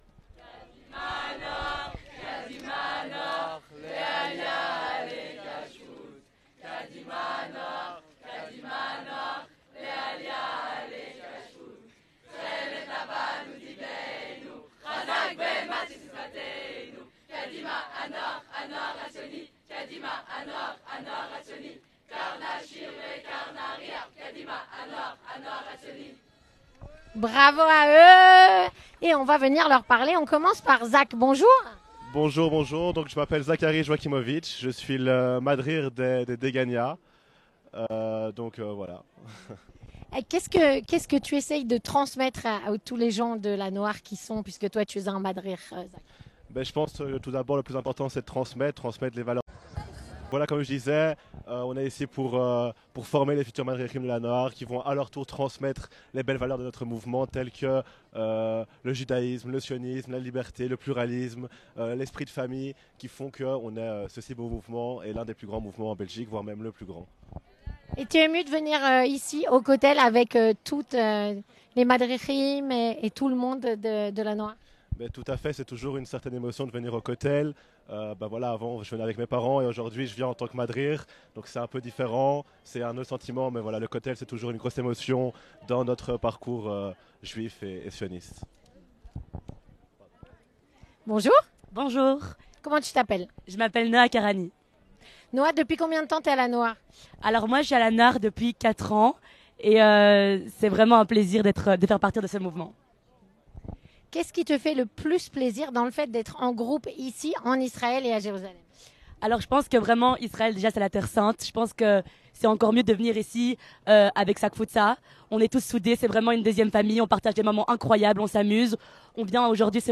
Discussion avec le groupe Degania de L’Hanoar Hatsioni (17/07/2023)